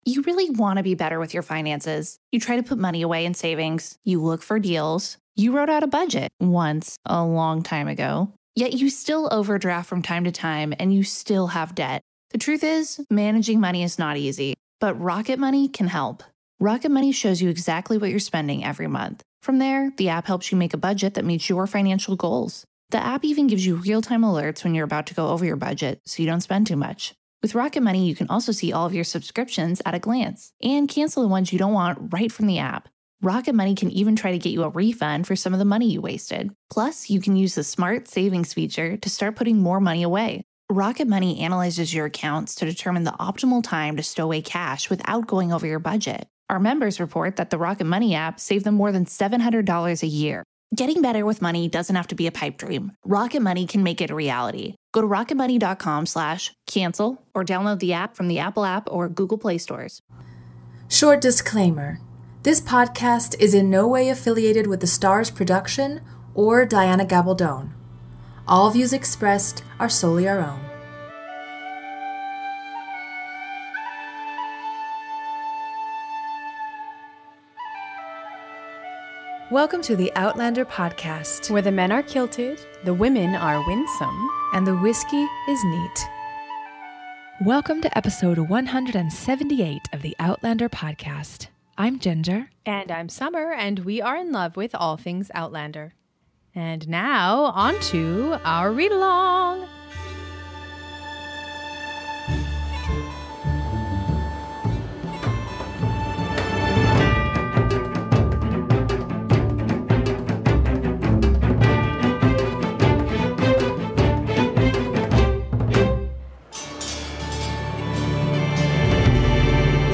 A special thanks to Recorded Books for permission to include snippets of the recording of 'Voyager. ' Support us on Patreon!